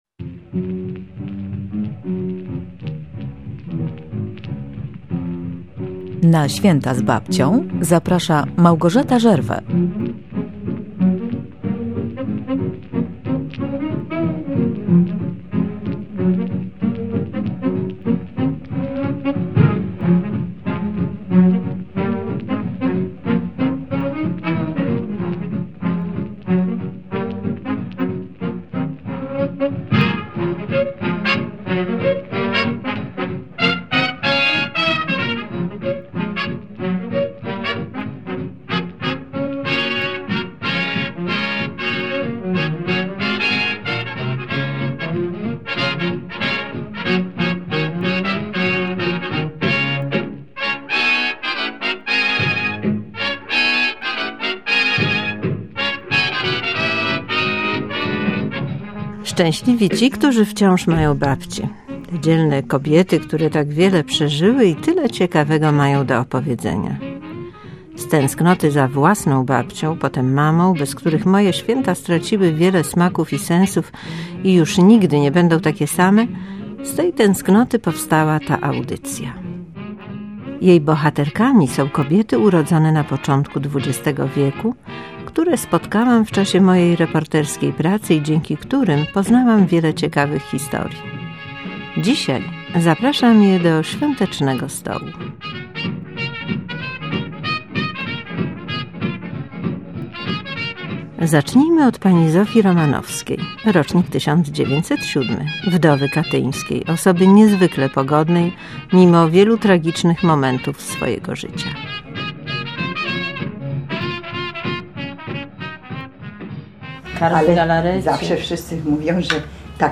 /audio/dok1/swietazbabcia.mp3 Tagi: dokument